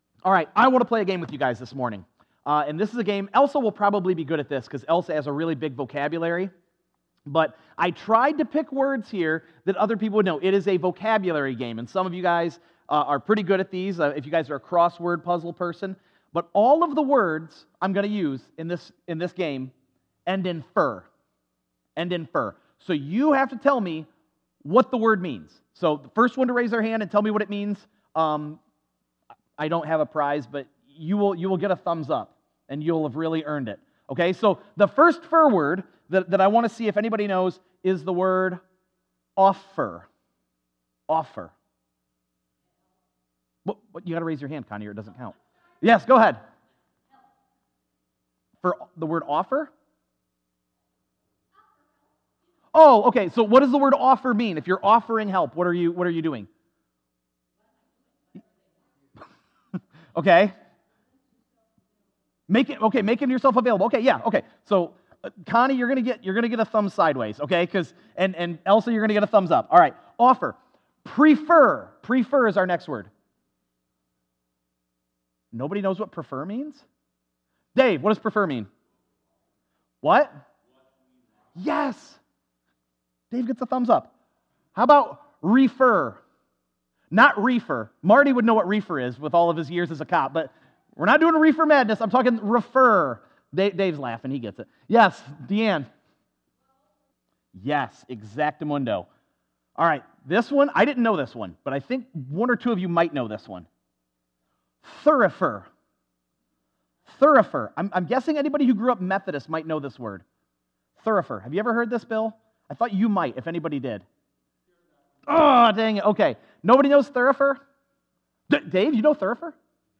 Sermons - First Brethren Church- Bryan Ohio